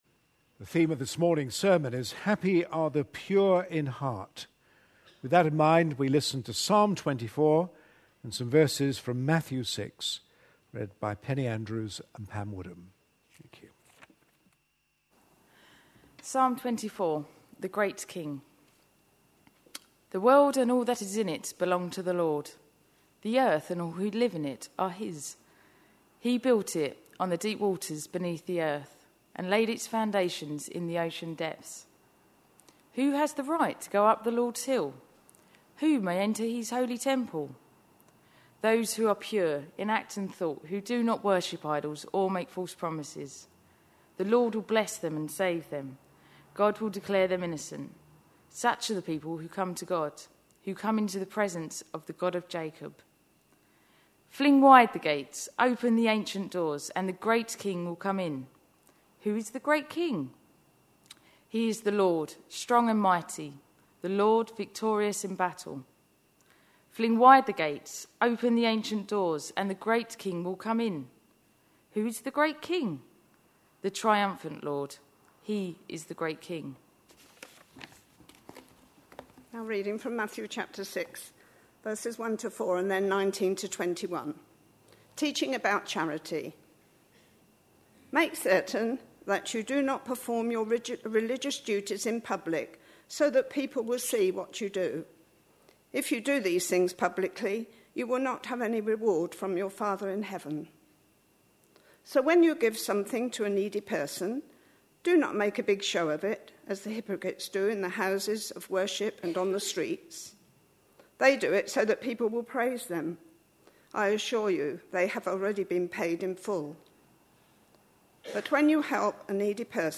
A sermon preached on 6th November, 2011, as part of our The Beatitudes. series.